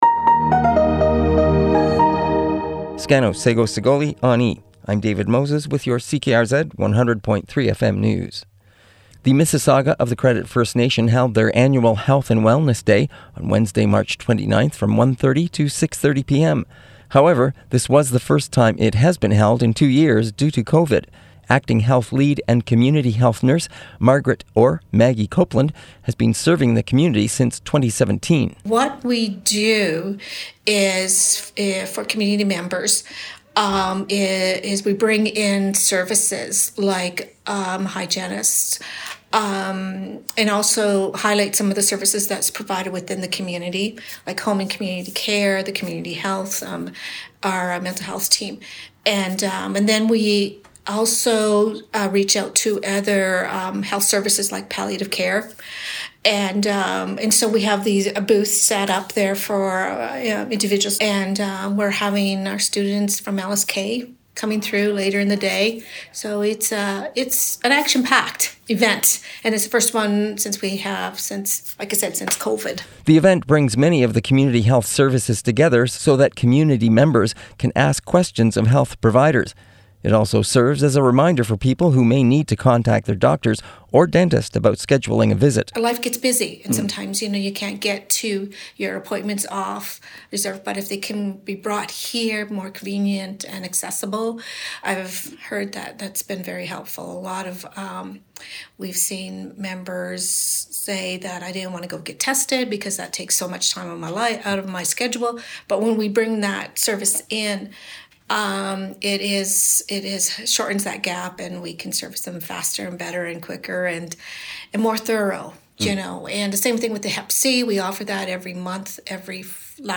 Listen to the report below: Download Audio Prev Previous Post Language summit on Six Nations addresses low speaker population and fluency Next Post Organizers hope expo sparks conversations about dying Next